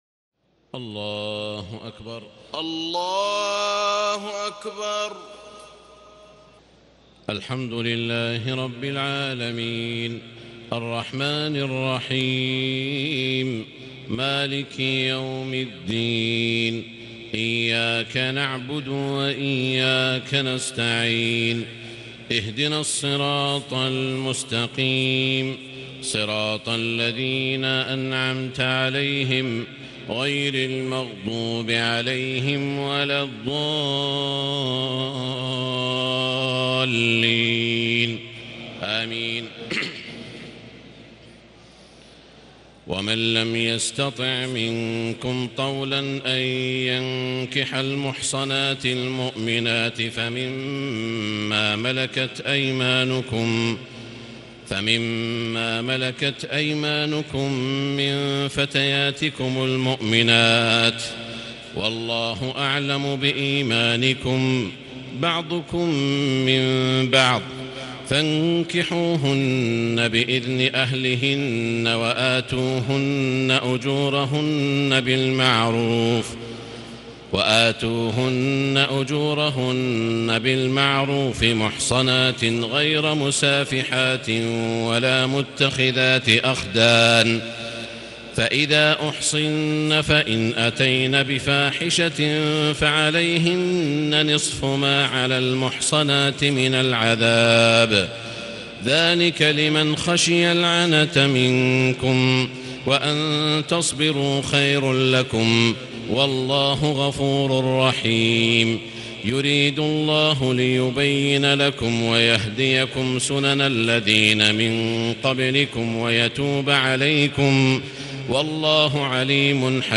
تهجد ليلة 25 رمضان 1440هـ من سورة النساء (25-99) Tahajjud 25 st night Ramadan 1440H from Surah An-Nisaa > تراويح الحرم المكي عام 1440 🕋 > التراويح - تلاوات الحرمين